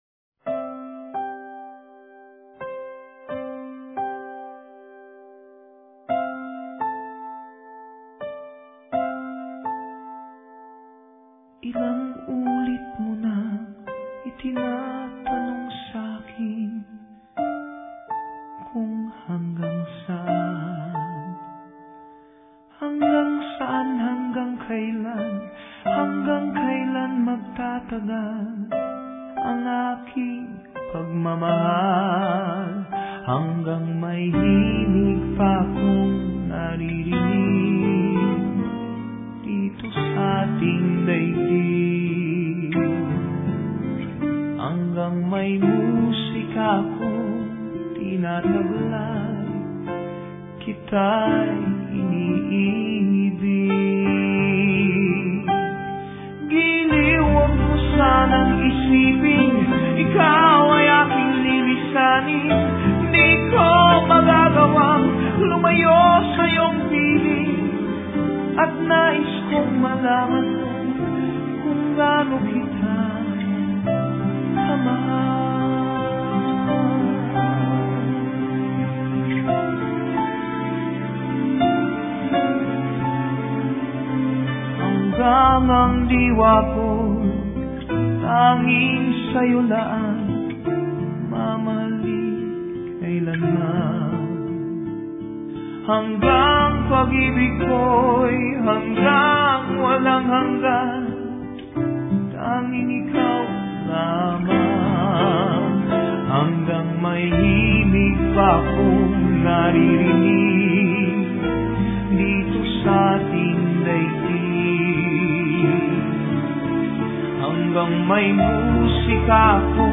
Tags: Sentimental Classic All time favorite Sweet Passionate